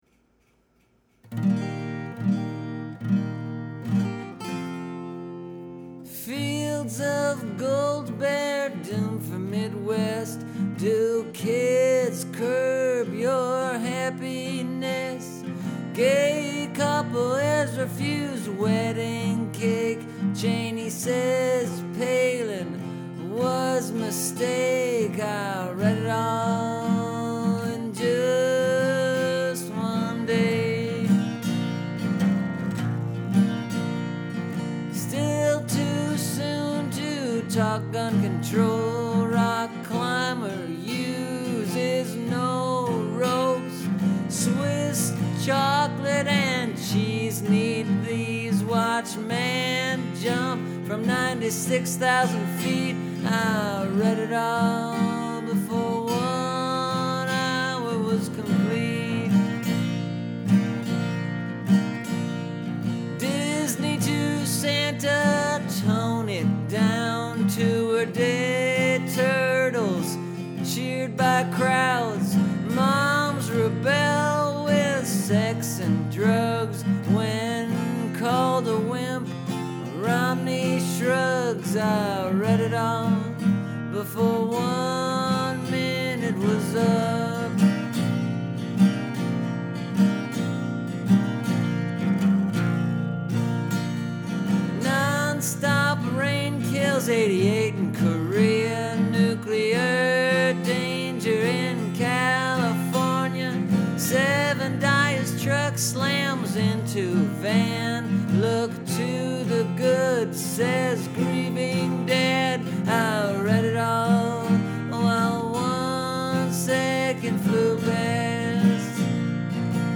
All in song form.
Share this: Tweet Share on Tumblr Related blues CNN folk music headline headlines home recording music News politics recording song songwriting WBEZ